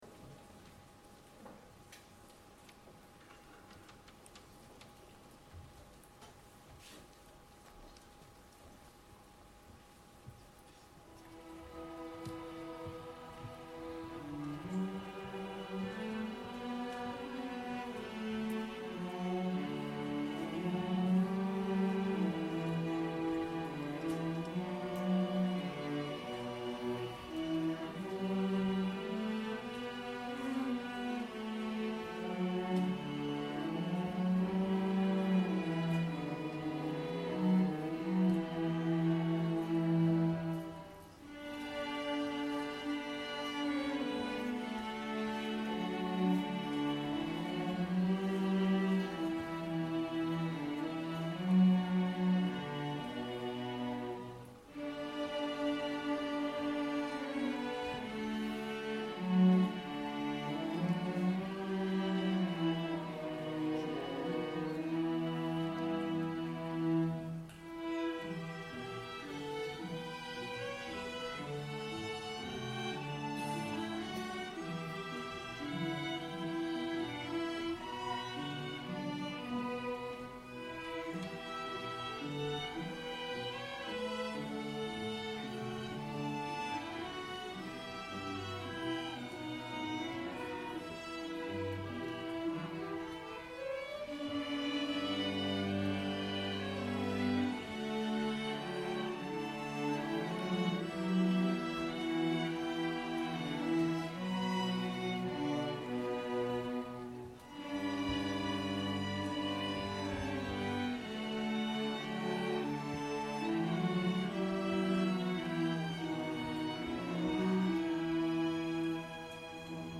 Strings Ensemble